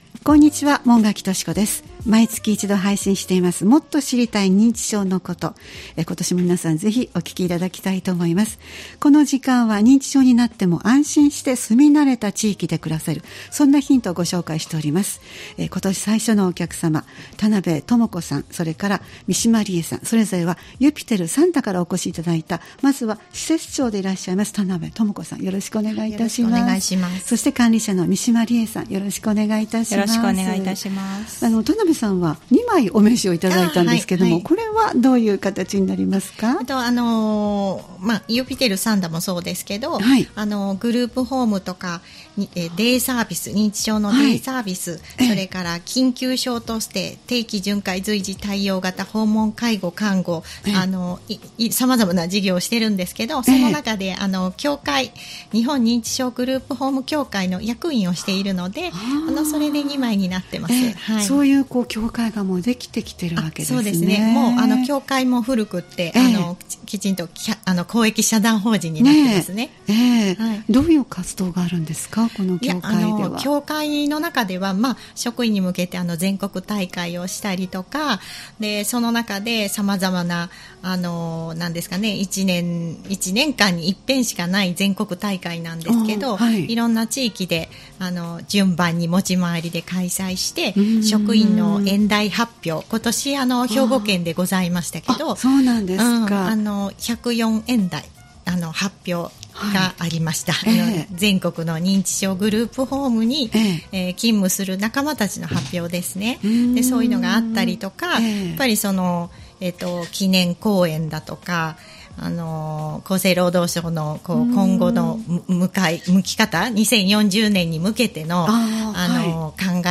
毎月第1月曜日に配信するポッドキャスト番組「もっと知りたい認知症のこと」 スタジオに専門の方をお迎えして、認知症に関連した情報、認知症予防の情報、介護や福祉サービスなどを紹介していただきます（再生ボタン▶を押すと番組が始まります）